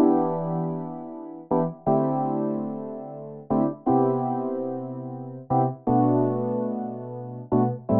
34 ElPiano PT1.wav